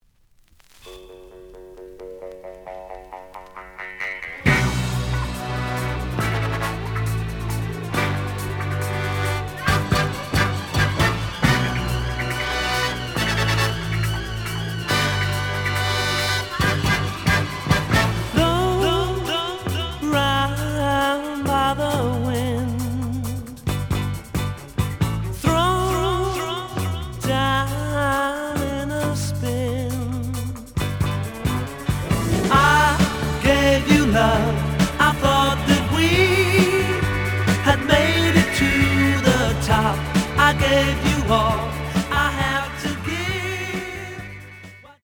The audio sample is recorded from the actual item.
●Genre: Disco
Slight noise on beginning of A side, but almost plays good.